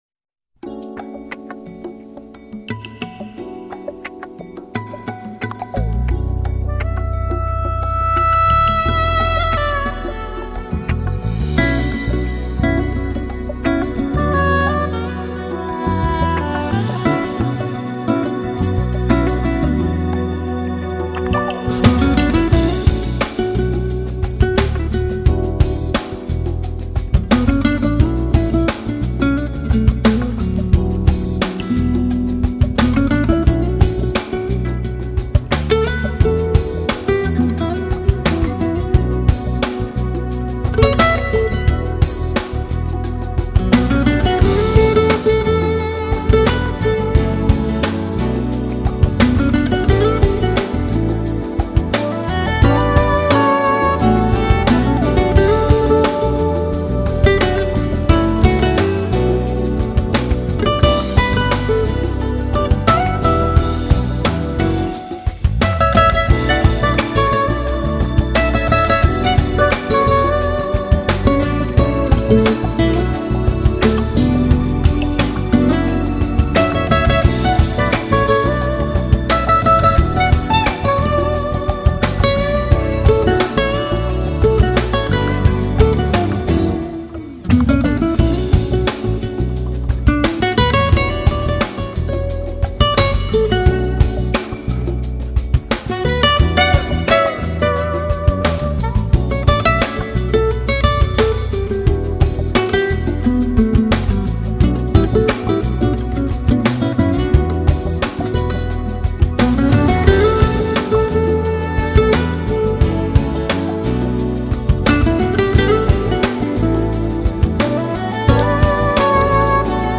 [爵士吉他]
音乐类型：Jazz